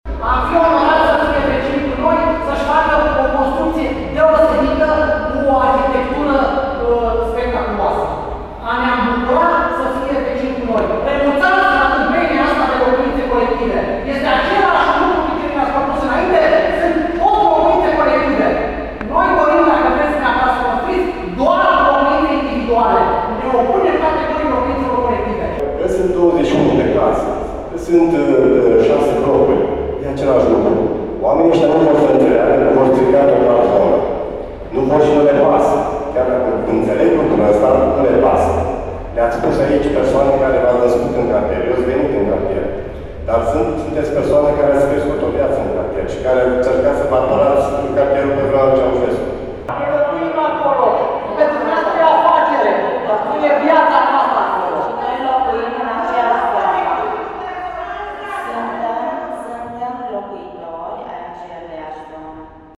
Dezbatere încinsă la Primăria Timișoara